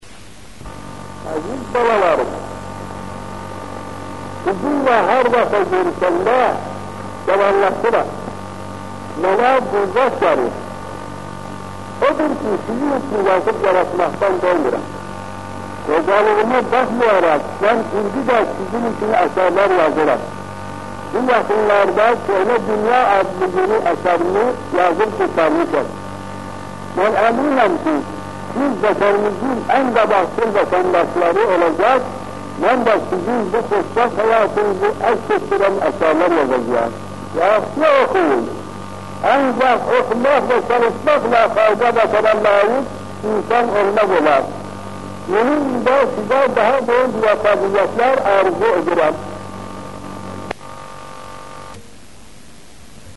VOICE RECORDING
Meeting with children in the Pioneer Center, 1950.